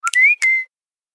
• Категория: Рингтон на смс